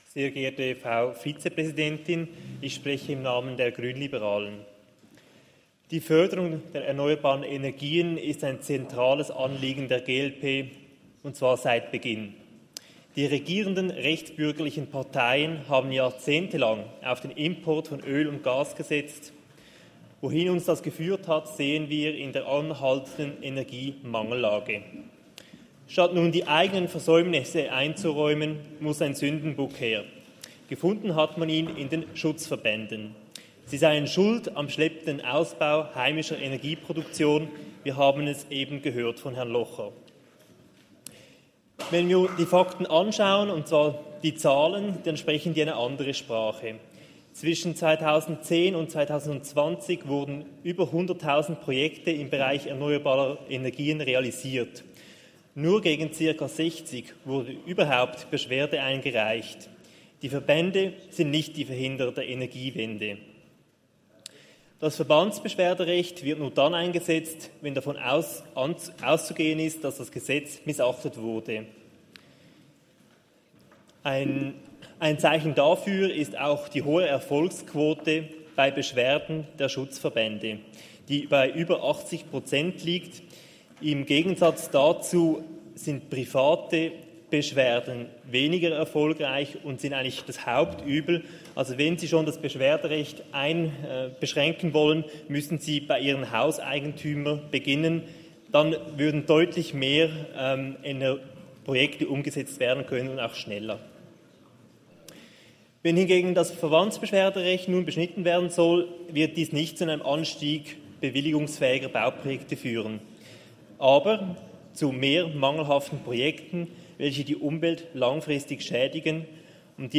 Session des Kantonsrates vom 18. bis 20. September 2023, Herbstsession
Bisig-Rapperswil-Jona (im Namen der GLP): Auf das Standesbegehren ist nicht einzutreten.